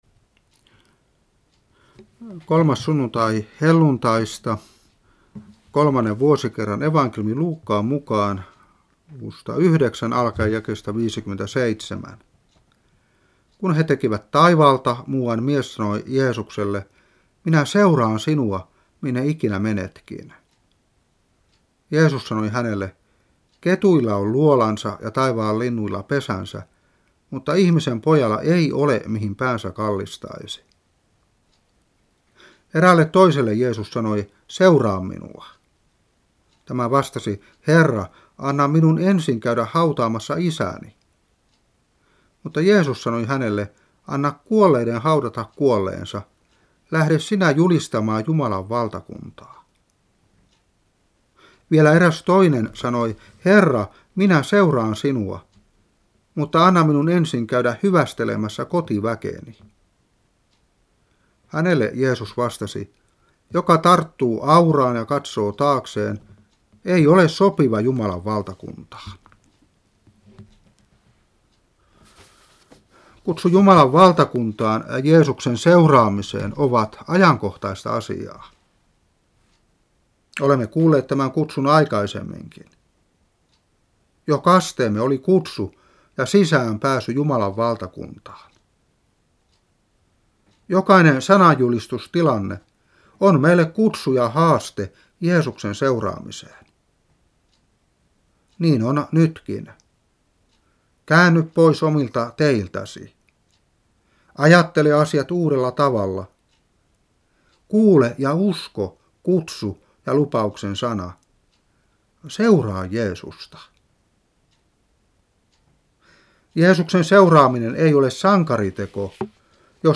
Saarna 2018-6. Luuk.9:57-62.